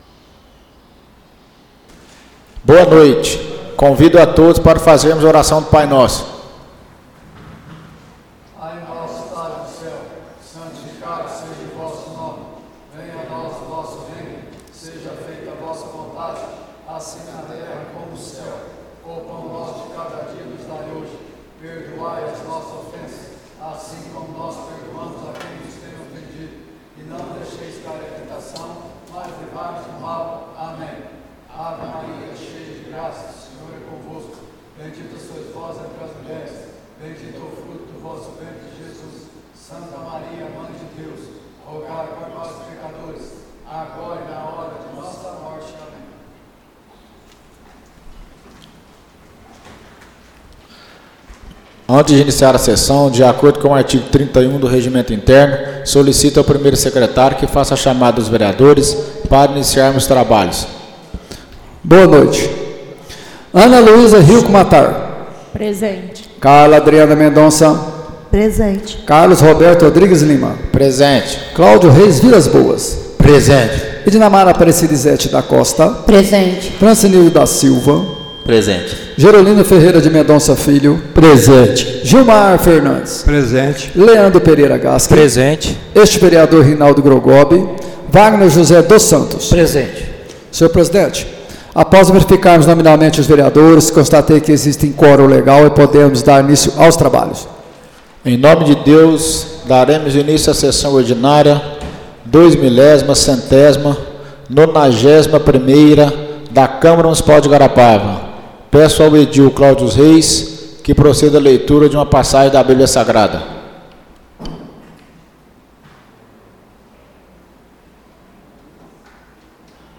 Áudio da Sessão Ordinária de 11/11/2024